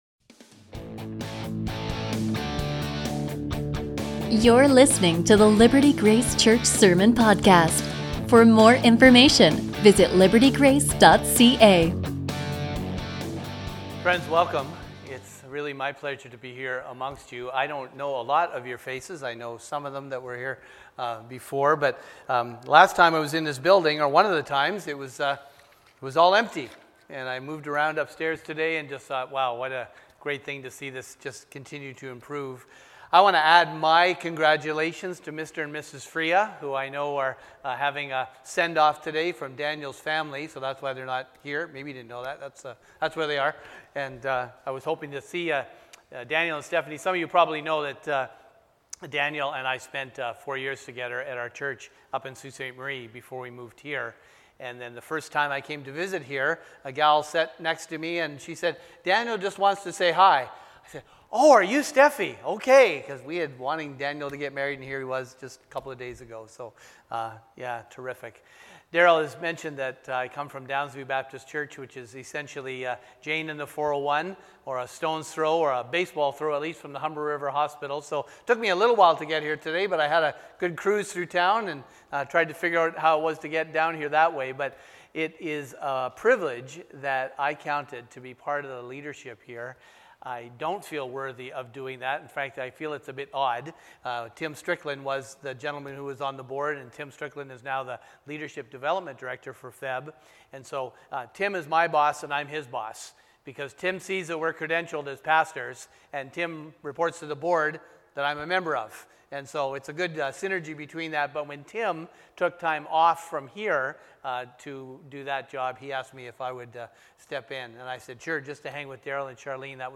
A sermon from Luke 3:3-14